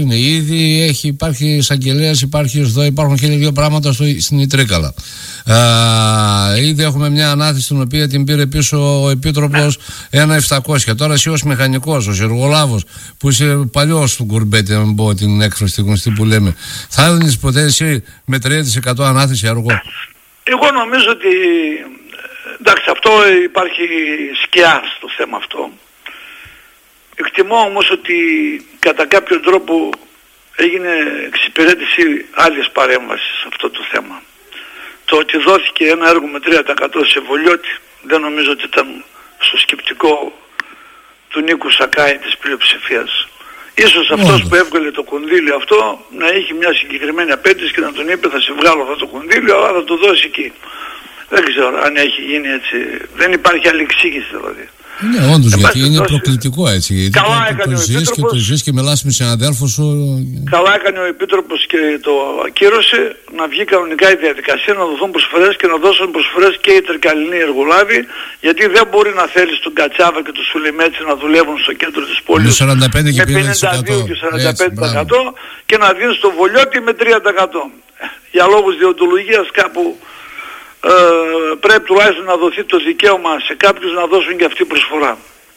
Ακούστε αναλυτικά τις δηλώσεις του πρώην βουλευτή και δημάρχου: